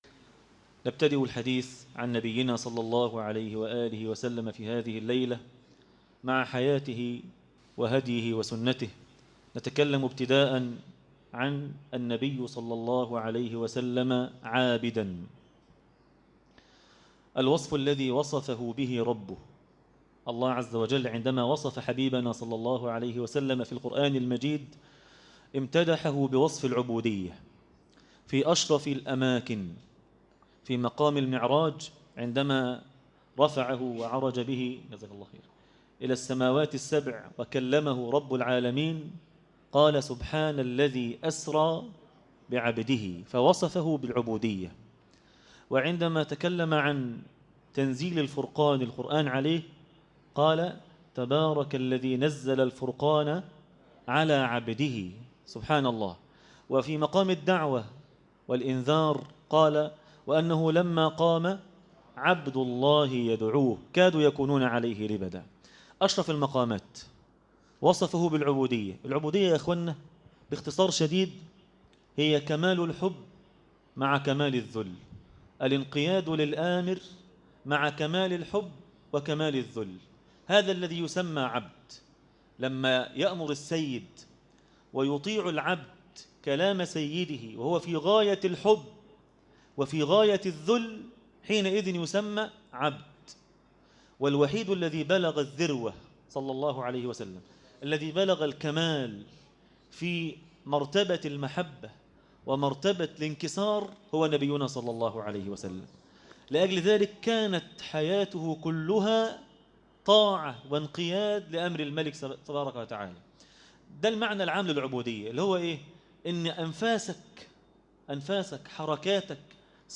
النبي (صلي الله عليه وسلم) عابدا - درس التراويح